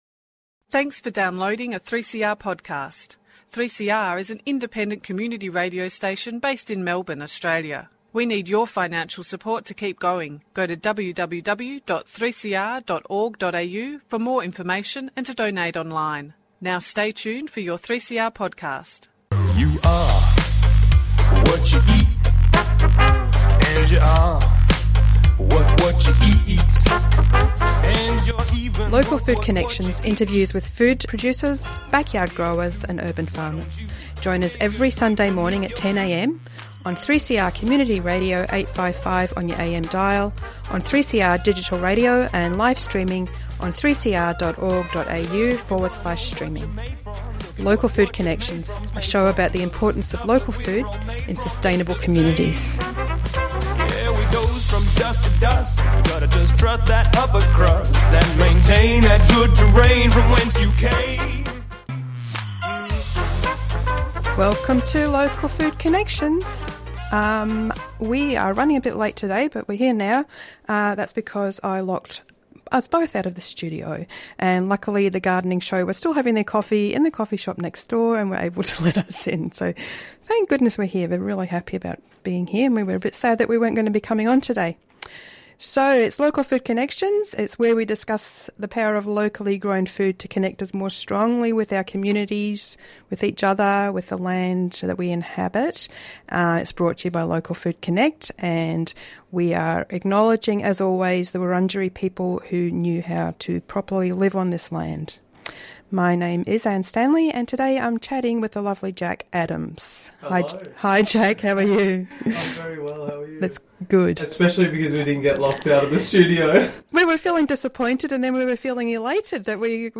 This interview was first aired on Local food Connections on 22 December 2024